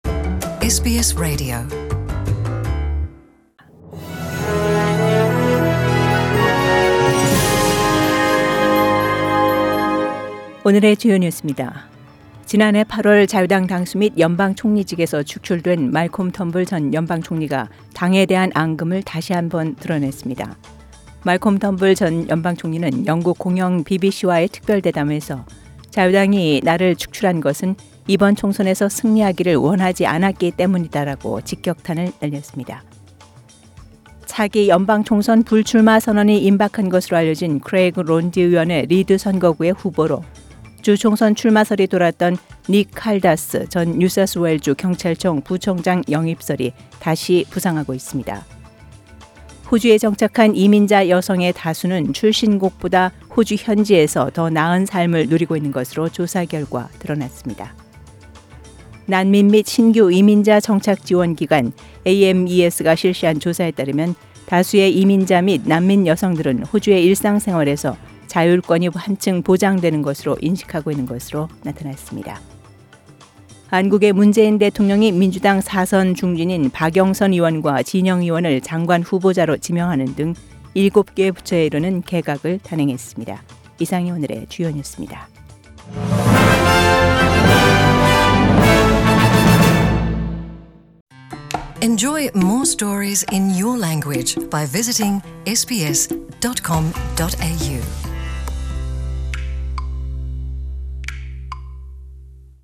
SBS Radio Korean News Bulletin Source: SBS Korean program